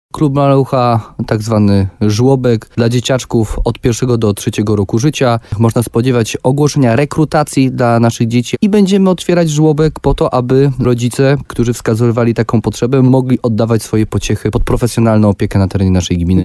Będziemy otwierać żłobek po to, aby rodzice, którzy wskazywali taką potrzebę, mogli oddawać swoje pociechy pod profesjonalną opiekę na terenie naszej gminy – mówił wójt gminy Gródek nad Dunajcem Jarosław Baziak.